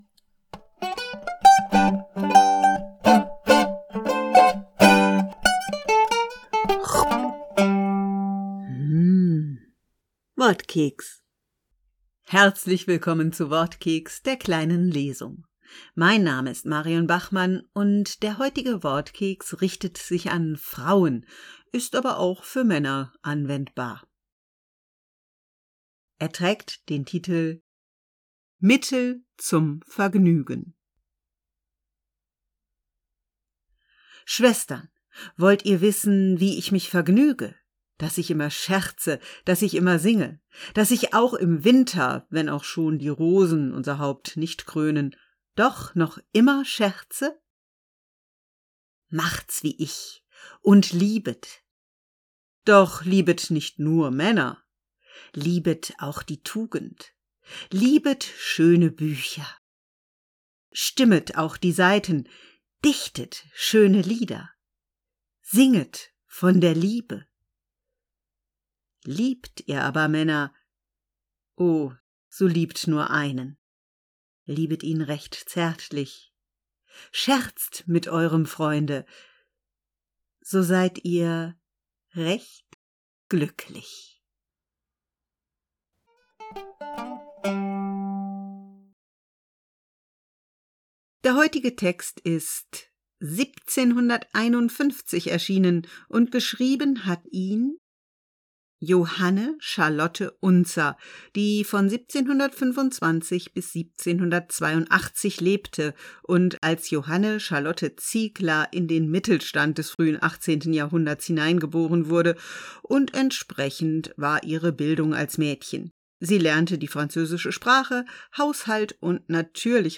Wortkeks - die kleine Lesung